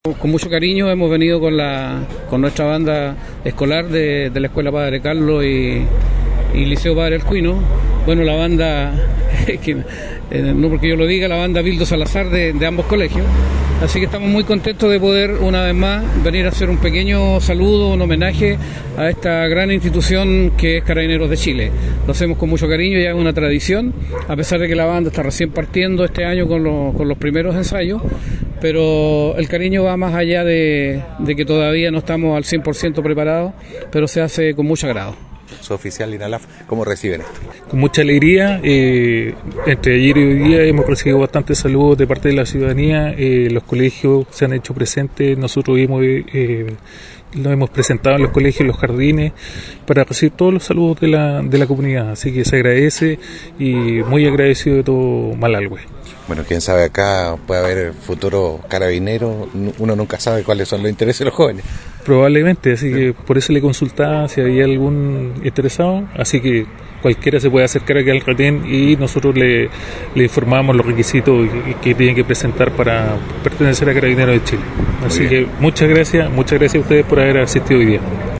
banda-carab.mp3